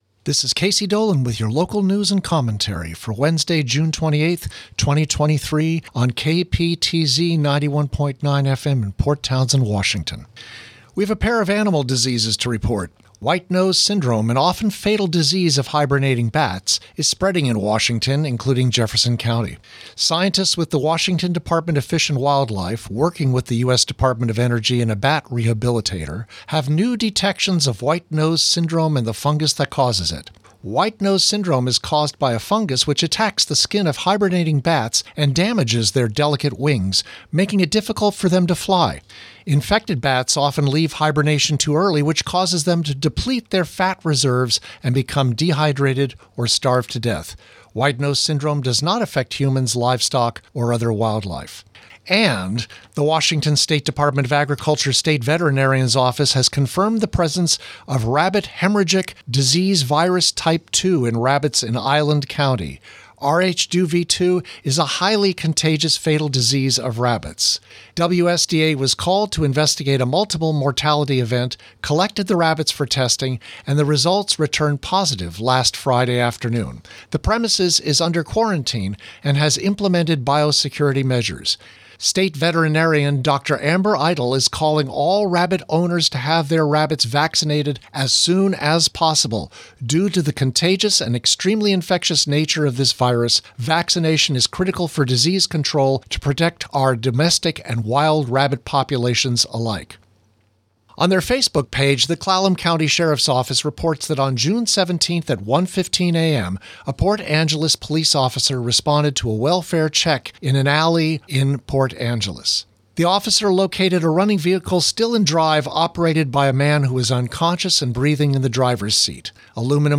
230628 Local News Wednesday